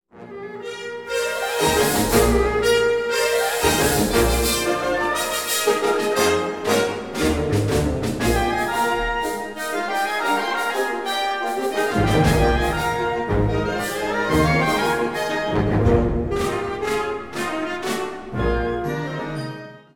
Besetzung Ha (Blasorchester)